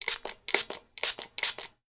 SPRAY_Manual_x4_mono.wav